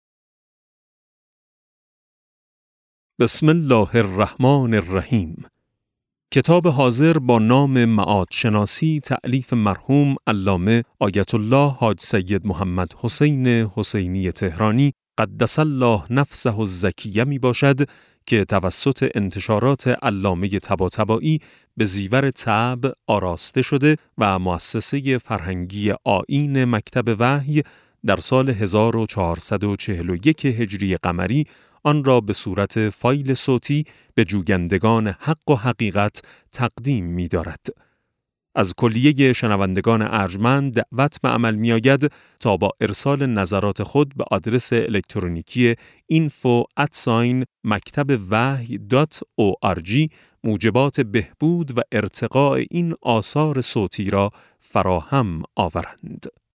کتاب صوتی معاد شناسی ج7 - جلسه1